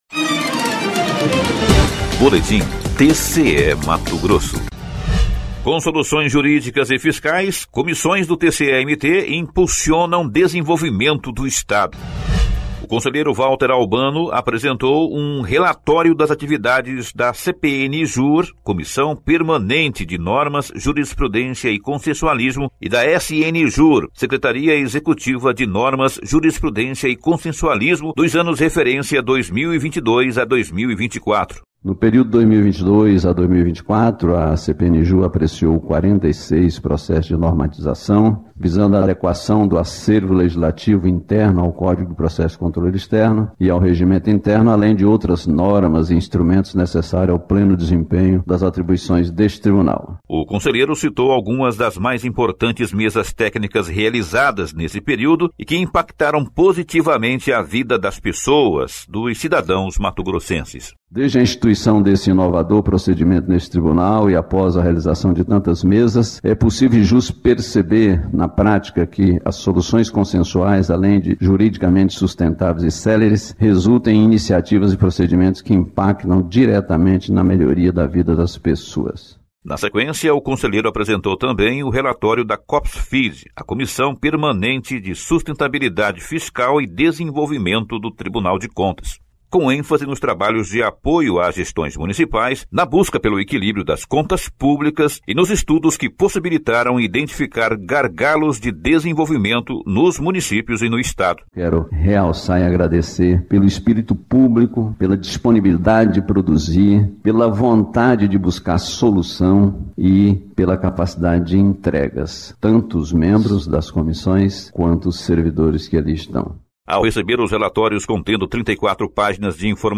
O conselheiro Valter Albano apresentou um balanço dos trabalhos desenvolvidos pelas comissões na sessão ordinária desta terça-feira (3).
Sonora: Valter Albano – conselheiro presidente da CPNJur e SNJur
Sonora: Sérgio Ricardo – conselheiro-presidente do TCE-MT